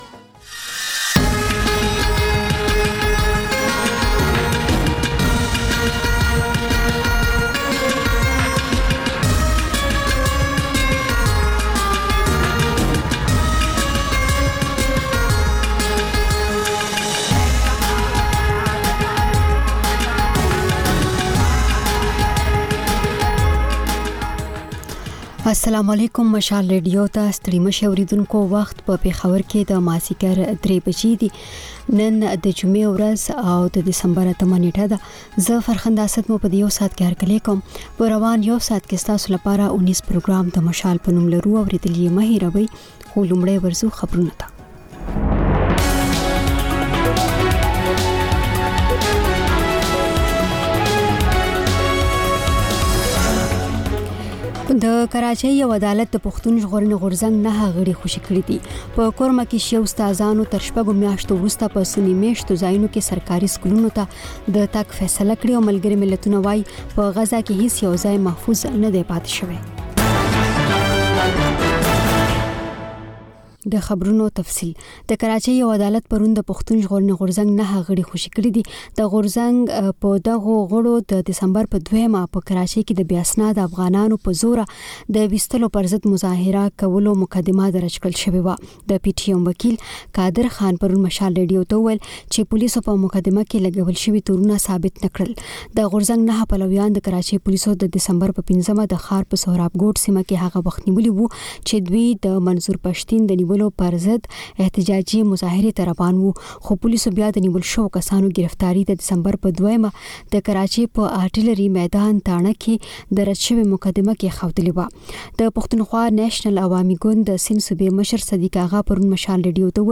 د مشال راډیو درېیمه یو ساعته ماسپښینۍ خپرونه. تر خبرونو وروسته، رپورټونه او شننې خپرېږي. ورسره اوونیزه خپرونه/خپرونې هم خپرېږي.